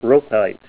Help on Name Pronunciation: Name Pronunciation: Roquesite + Pronunciation
Say ROQUESITE Help on Synonym: Synonym: ICSD 28739   PDF 27-159